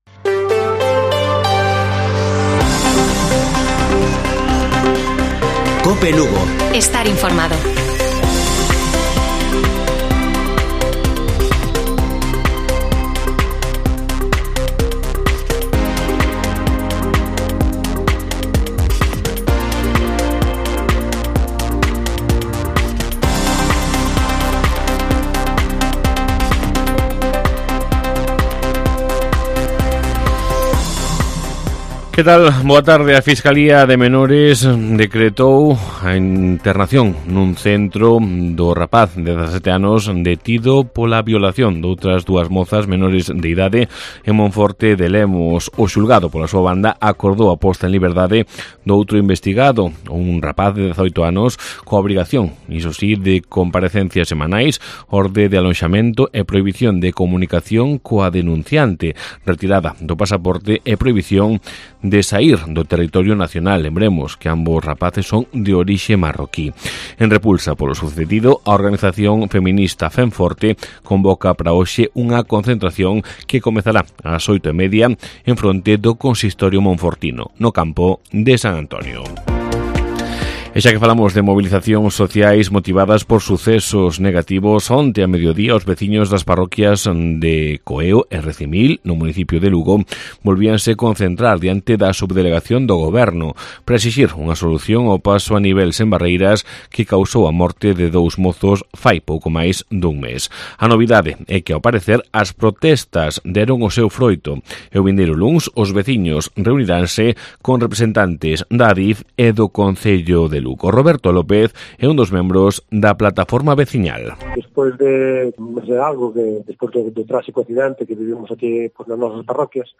Informativo Mediodía de Cope Lugo. 17 de agosto. 14:20 horas